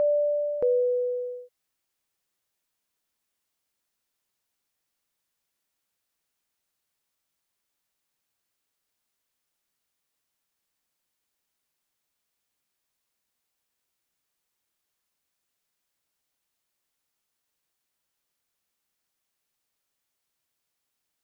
chime
chime ding game-show right-answer sound effect free sound royalty free Sound Effects